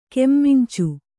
♪ kemmincu